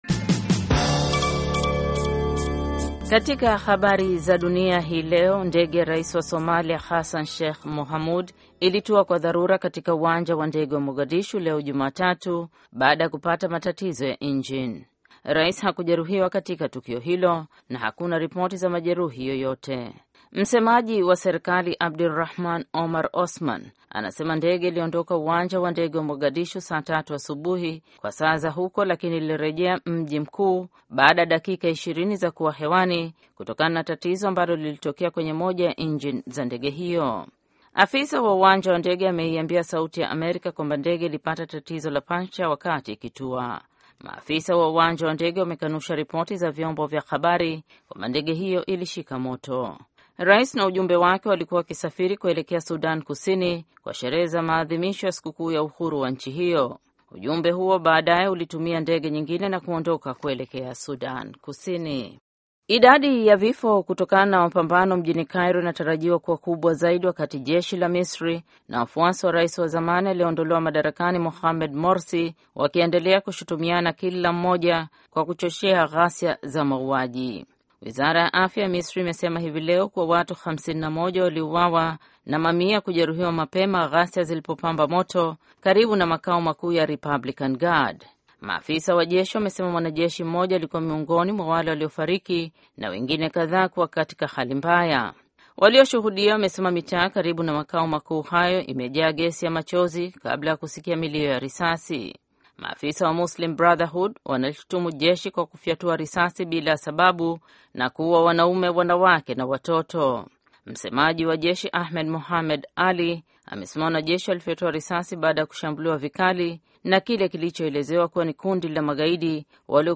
Taarifa ya Habari VOA Swahili - 6:16